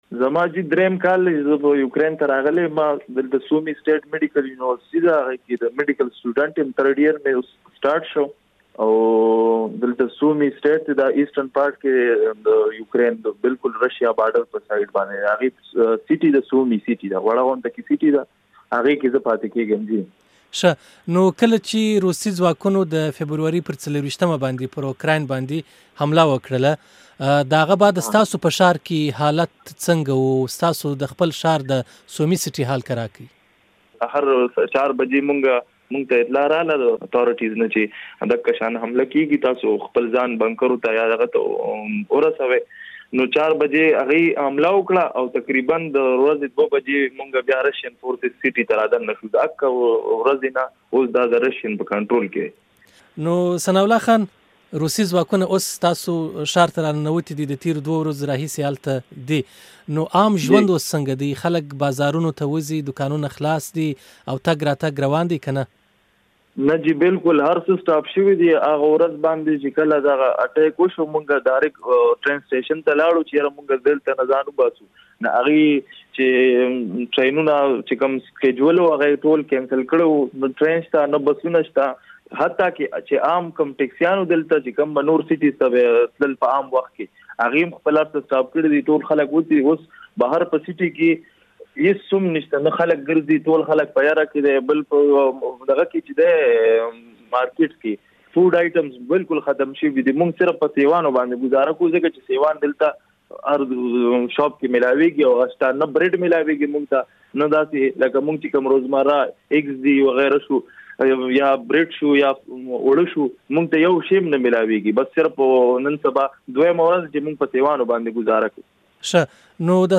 په اوکراین کې له یو پښتون زده کوونکي سره مرکه دلته واورئ